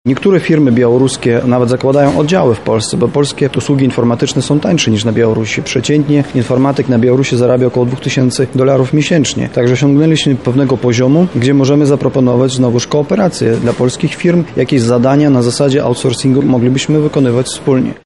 Trwa II Wschodnie Forum Biznesu.